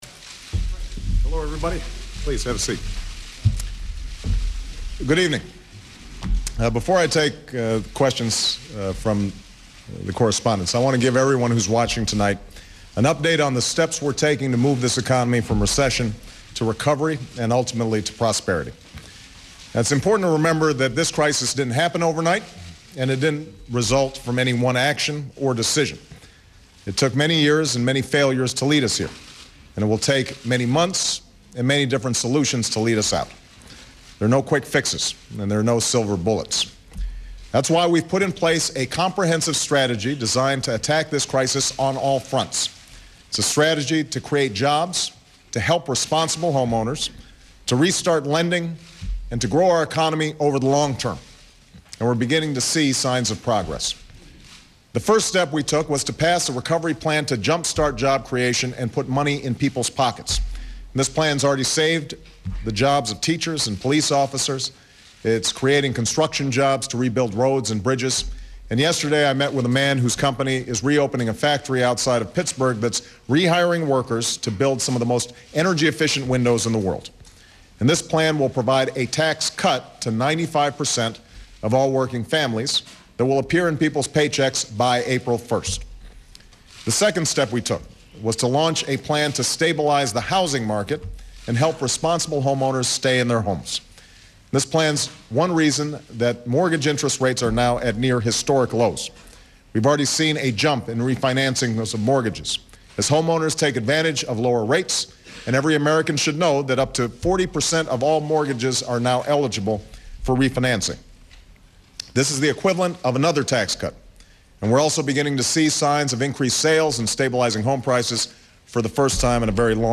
U.S. President Barack Obama holds his second prime time news conference on the economy
President Obama defends the 2010 budget and gives a positive outlook on the recovery. Obama answers questions from reporters involving cuts and increases in taxes, the national debt for the next generation, border control, the procurement system, and the AIG bonuses.
Broadcast on C-SPAN, Mar. 24, 2009.